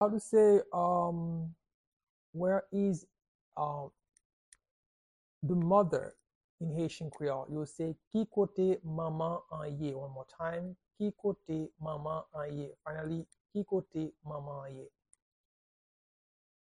Pronunciation and Transcript:
How-to-say-Where-is-the-mother-in-Haitian-Creole-–-Ki-kote-manman-an-ye-pronunciation-by-a-Haitian-teacher.mp3